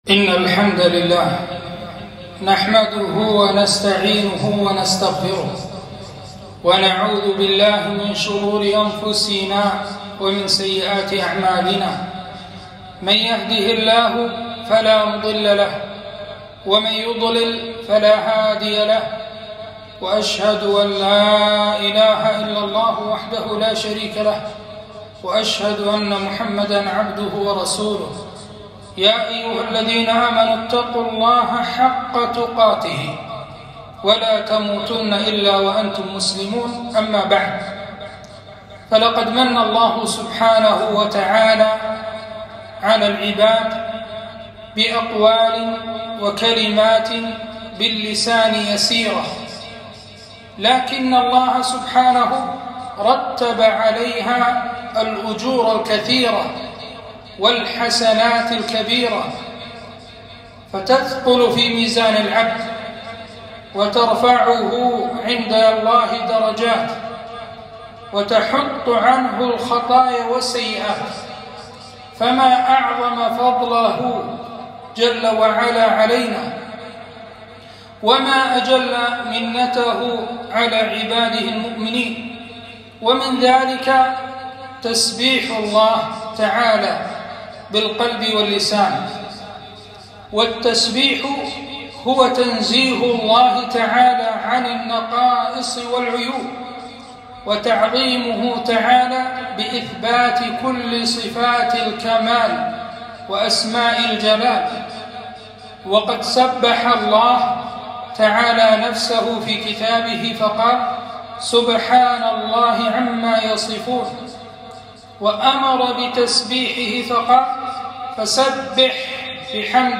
خطبة - فضائل التسبيح